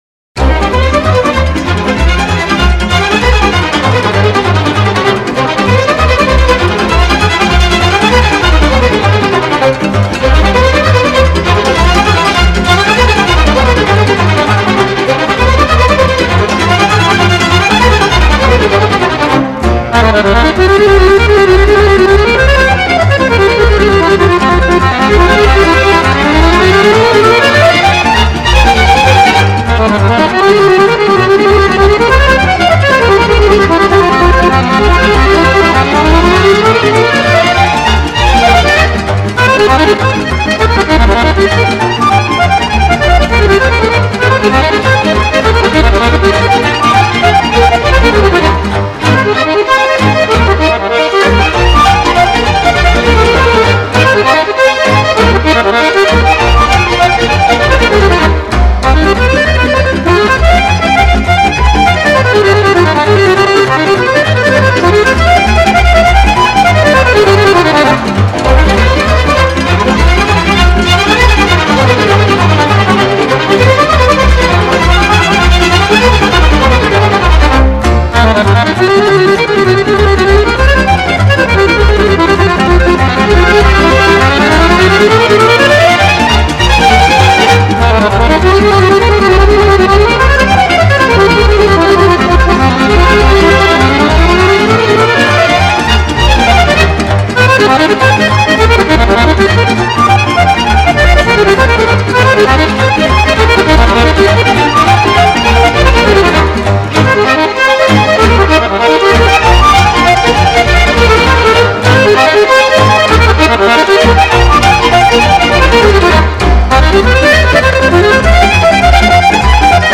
Играет молдавский аккордеонист
Народные песни и танцы